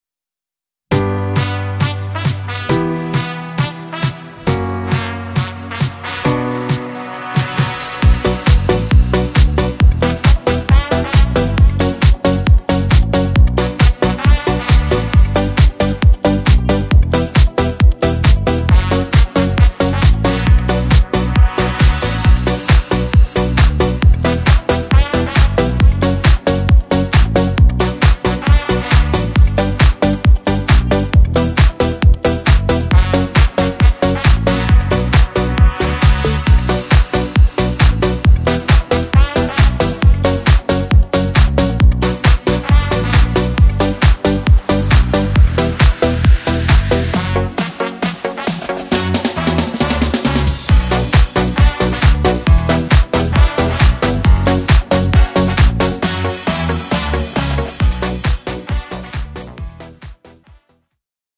podkład muzyczny
Disco Polo